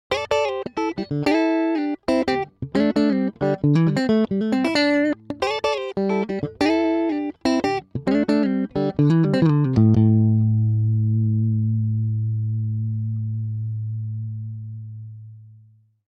autopan.mp3